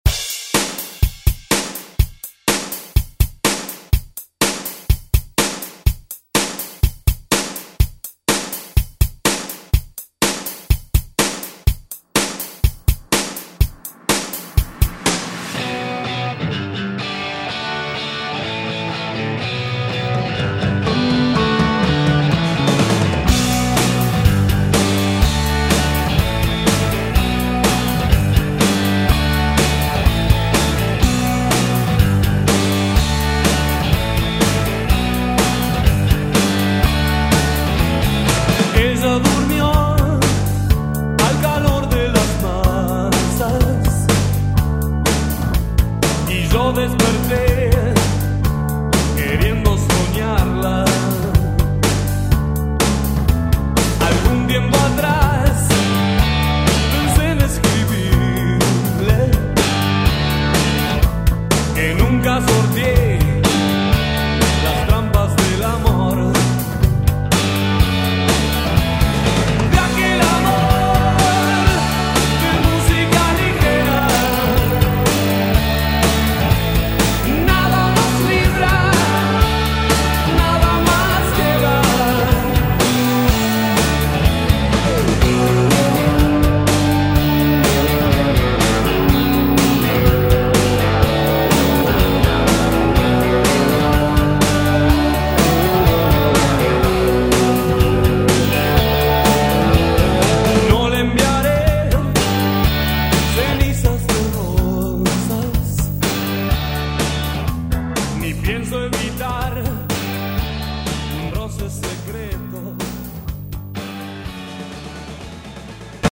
Electronic Pop Music Extended ReDrum
120 bpm